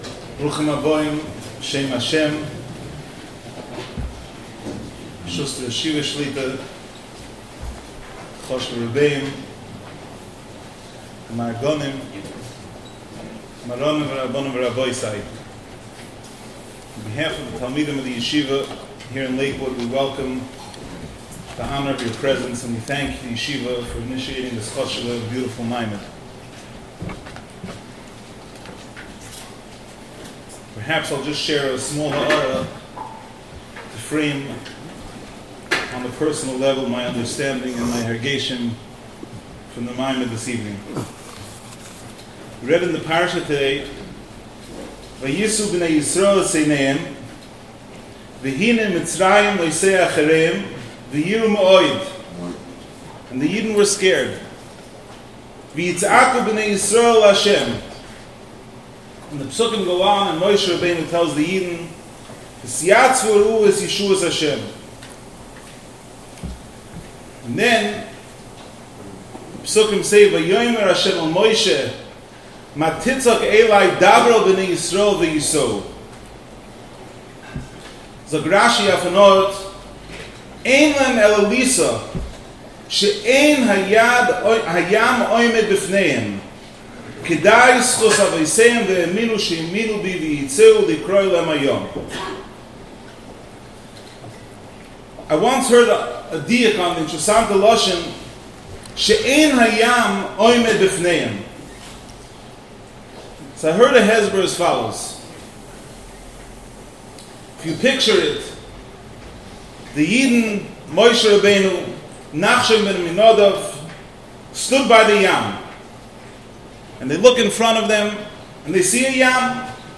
lakewood-melaveh-malka-2019.mp3